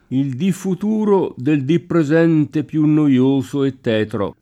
[di+] s. m. («giorno») — es.: il dì futuro Del dì presente più noioso e tetro [